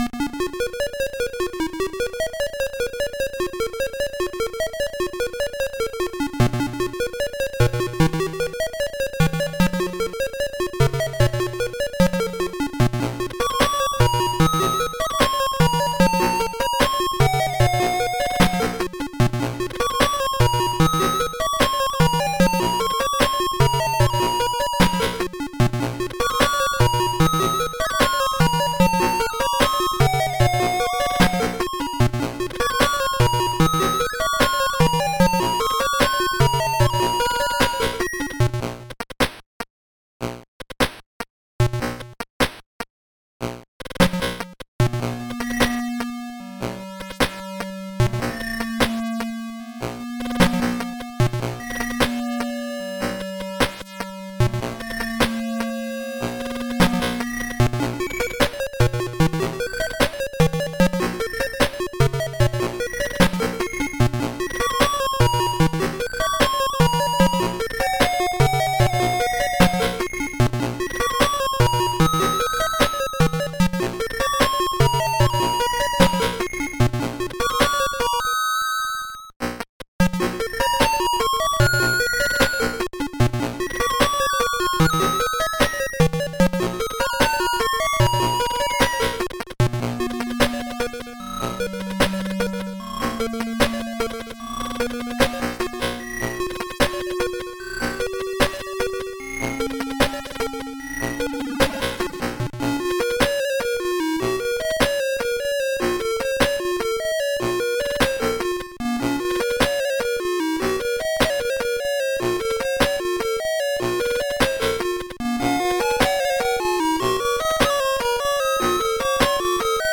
This piece has the sound of the old TI-99/4A computer, which only had harsh square waves and a noise channel. Musically, its sparse percussion and fluttery sounds carry a "creepy beepy" atmosphere.
Three square waves and a noise channel! a36c0977c44a71a4.mp3 1+